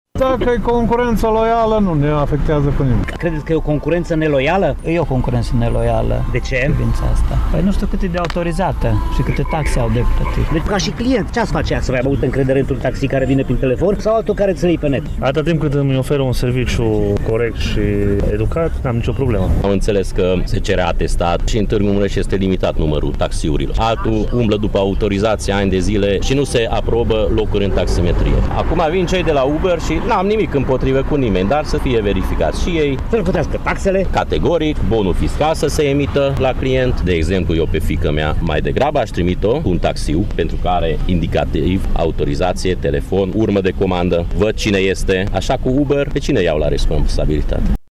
Taximetriștii din Tg. Mureș susțin că vor fi afectați, în cazul în care concurența e neloială, iar ei își plătesc în continuare taxele: